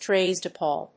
アクセント・音節trádes・pèople